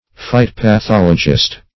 phytopathologist - definition of phytopathologist - synonyms, pronunciation, spelling from Free Dictionary
Search Result for " phytopathologist" : The Collaborative International Dictionary of English v.0.48: Phytopathologist \Phy`to*pa*thol"o*gist\, n. One skilled in diseases of plants.